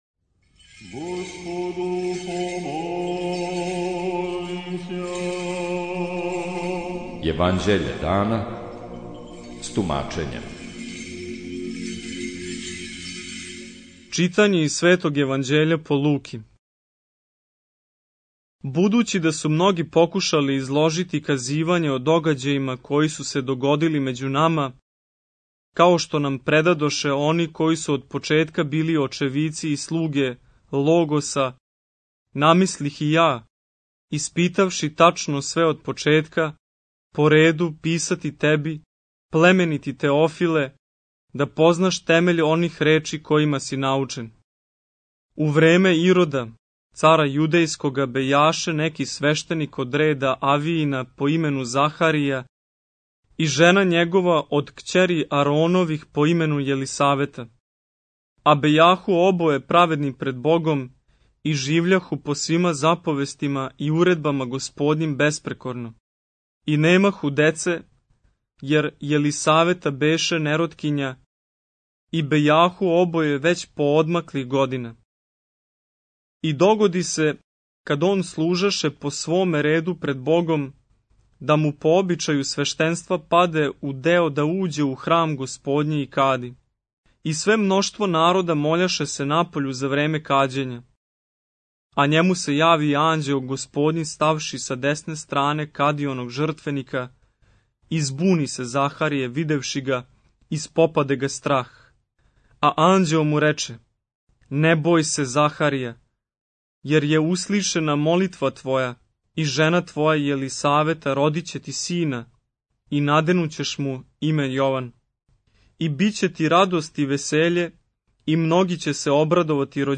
Читање Светог Јеванђеља по Луки за дан 07.07.2025. Зачало 1.